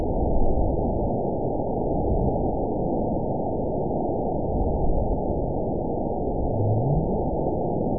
event 912624 date 03/30/22 time 12:47:55 GMT (3 years, 1 month ago) score 9.61 location TSS-AB04 detected by nrw target species NRW annotations +NRW Spectrogram: Frequency (kHz) vs. Time (s) audio not available .wav